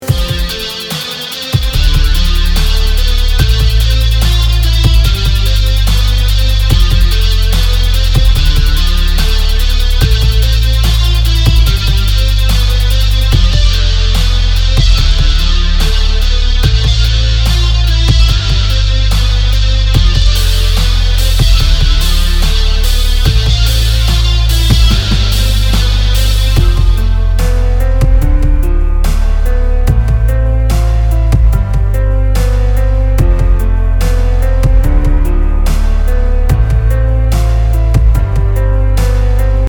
• Качество: 192, Stereo
мелодичные
без слов
клавишные
пианино
instrumental hip-hop
минус
Самодельная инструменталка песни